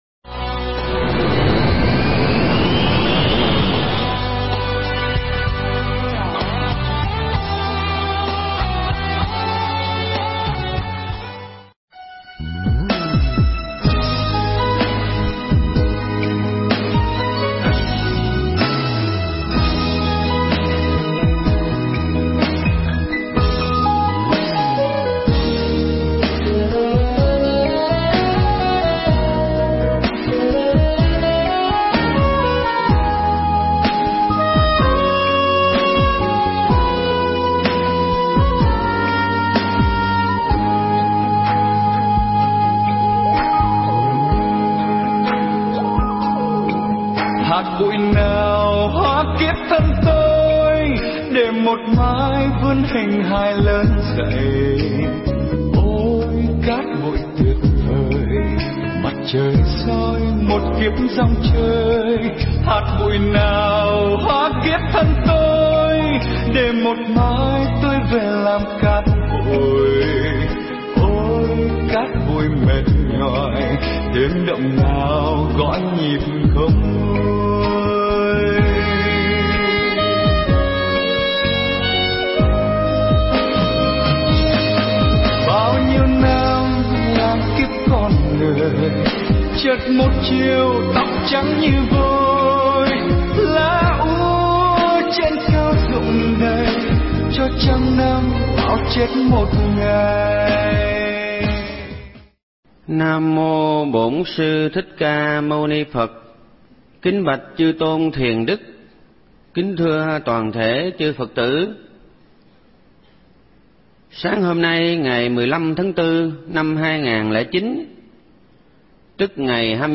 Nghe Mp3 thuyết pháp Cát Bụi